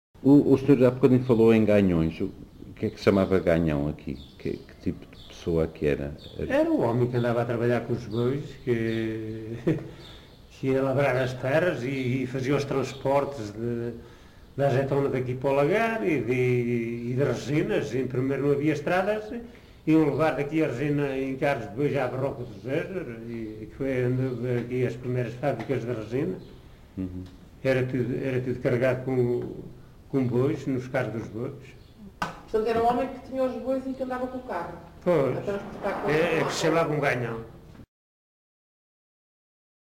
Porto de Vacas, excerto 15
LocalidadePorto de Vacas (Pampilhosa da Serra, Coimbra)